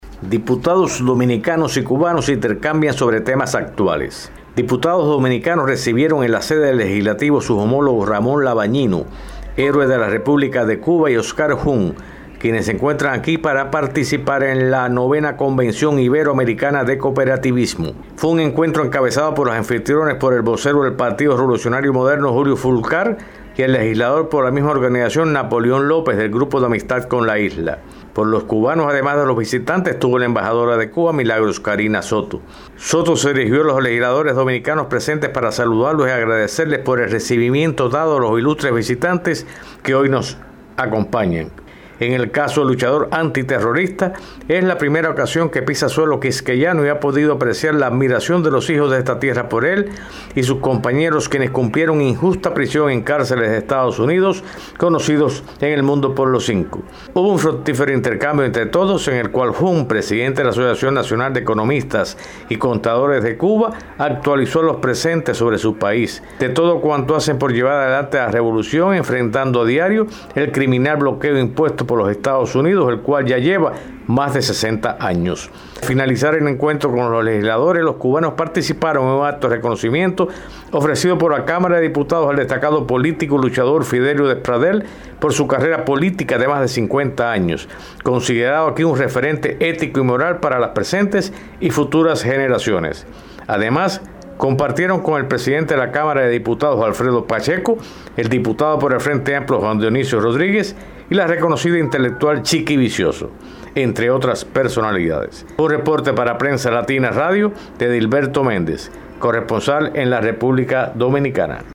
desde Santo domingo